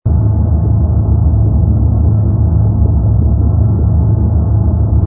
rumble_bw_h_fighter.wav